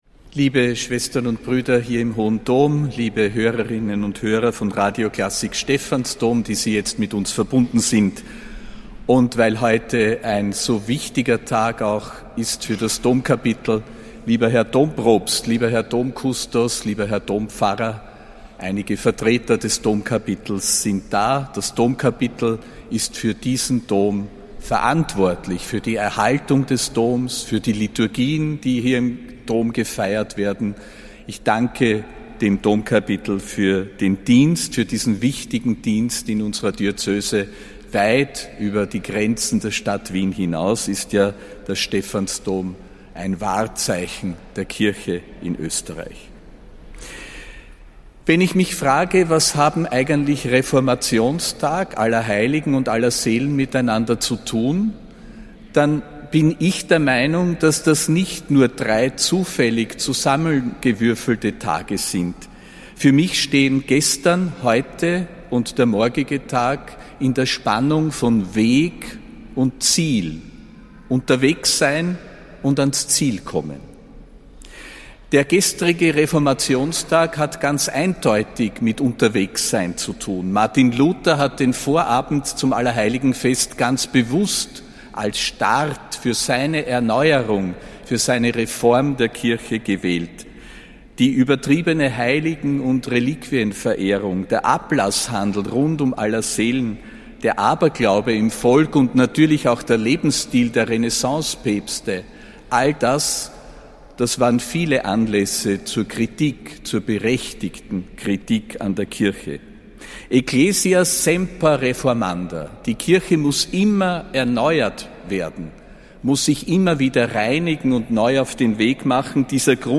Predigt des Ernannten Erzbischofs Josef Grünwidl zu Allerheiligen, am 1. November 2025.